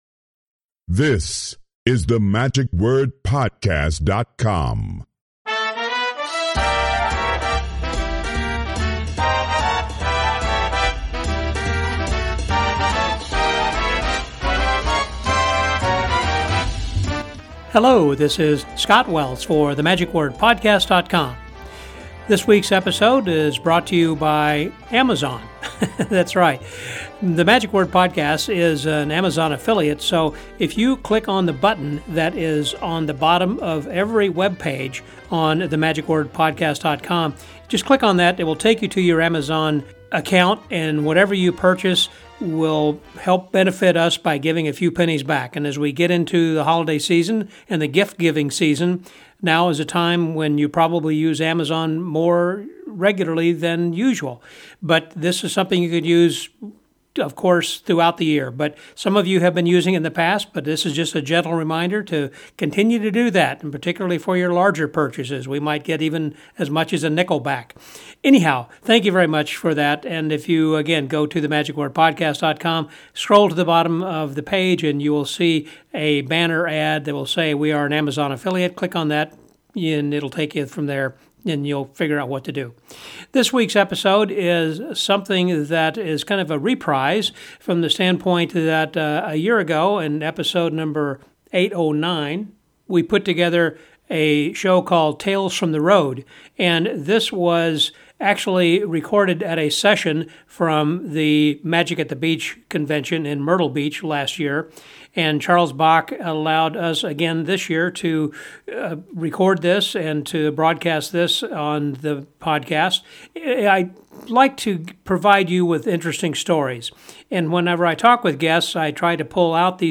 During the Magic At The Beach 2024 convention, one of the sessions was “Tales From The Road” which was reprised from last year, since it was such a success then.